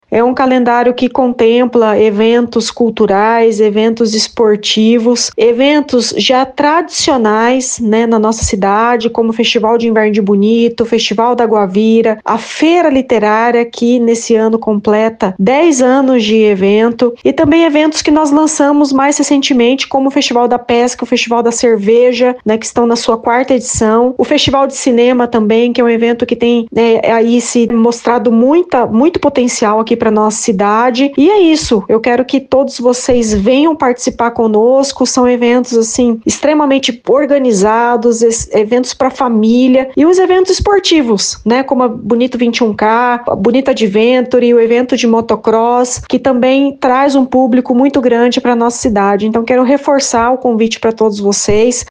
A secretária municipal de Turismo, Juliane Salvadori, destacou a expectativa positiva em relação à programação.